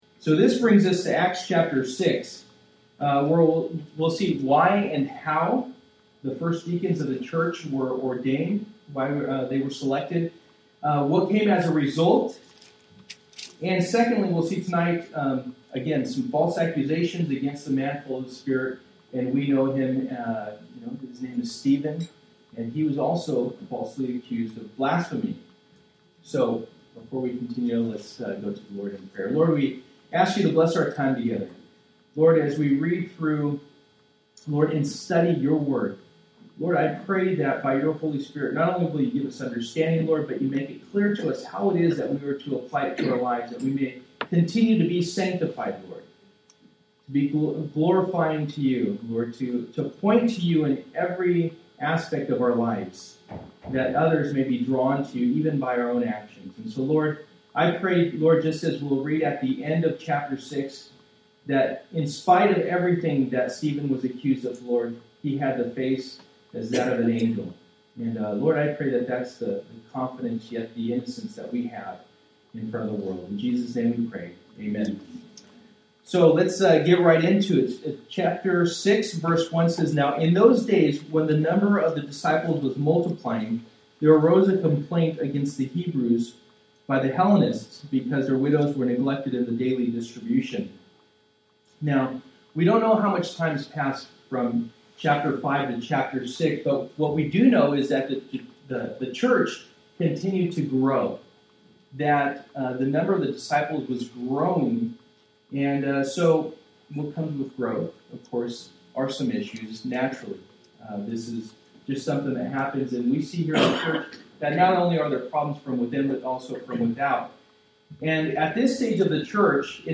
Passage: Acts 6:1-15 Service: Wednesday Night %todo_render% « A Peculiar People